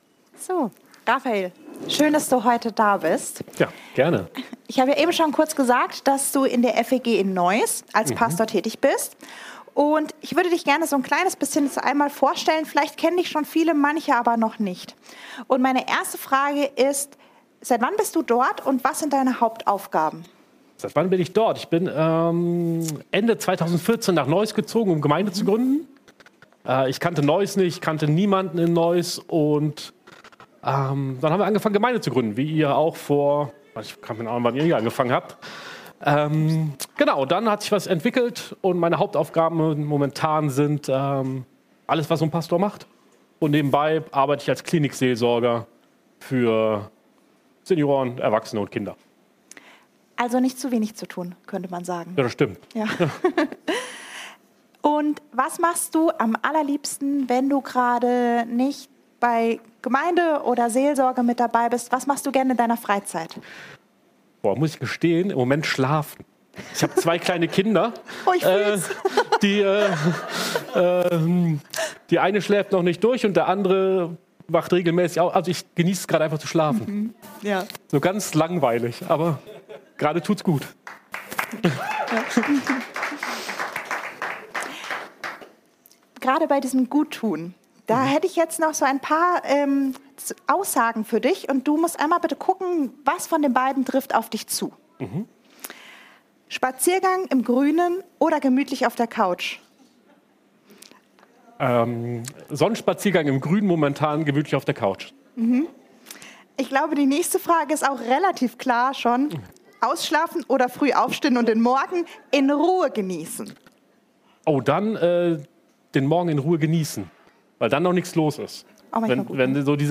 Heilung – Anders als erwartet ~ Predigt-Podcast von unterwegs FeG Mönchengladbach Podcast